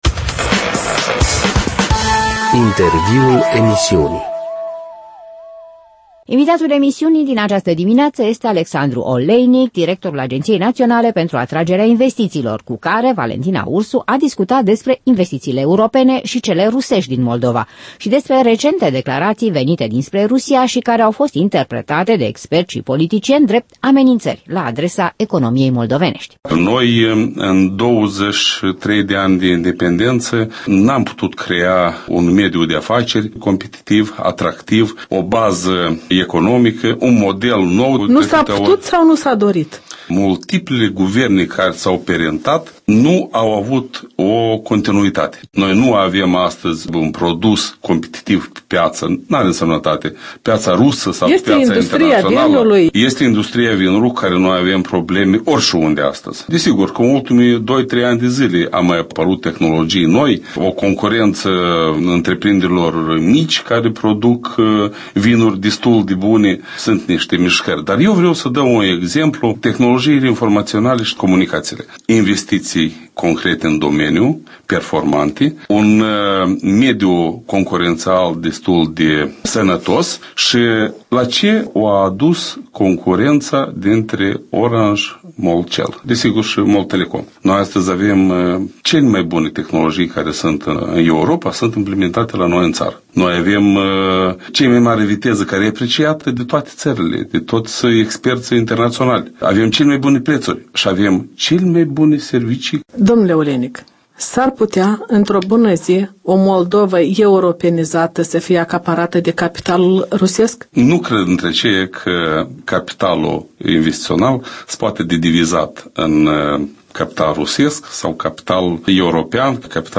Un interviu cu Alexandru Oleinic, directorul Agenției Naționale pentru Atragerea Investițiilor